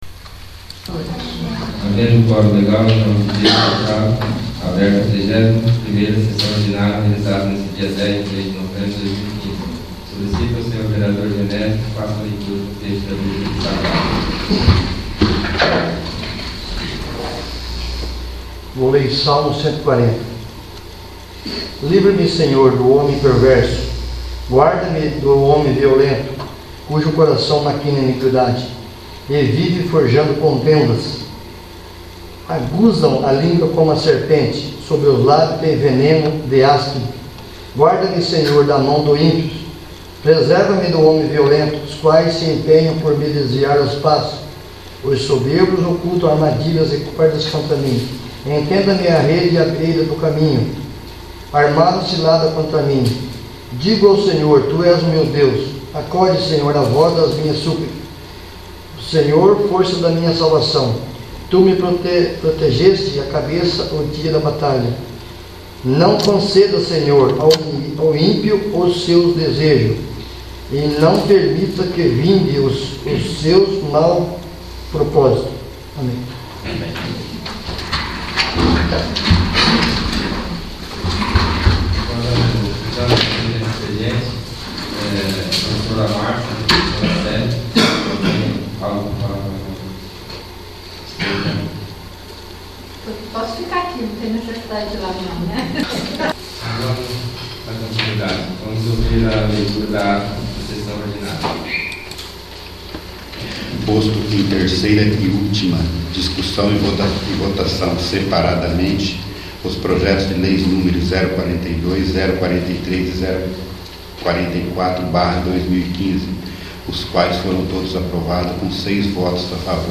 31º. Sessão Ordinária 10/11/2015
31º. Sessão Ordinária